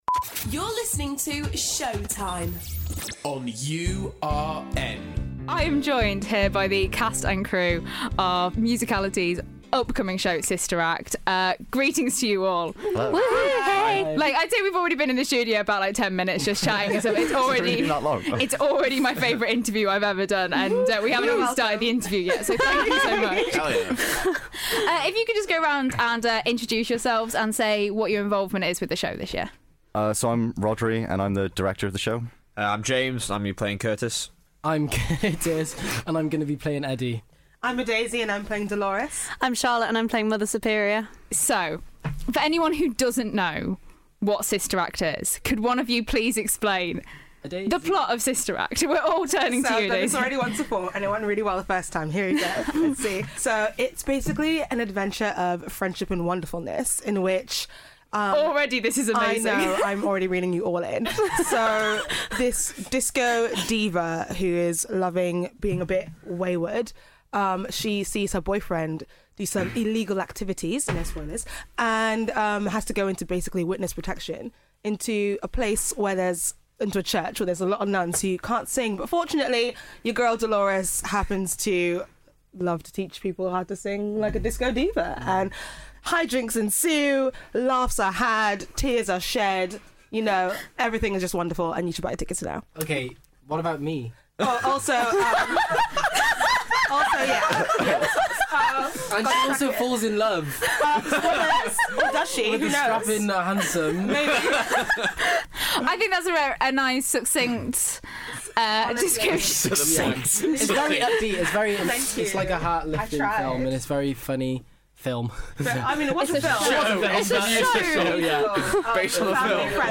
Showtime - Sister Act Interview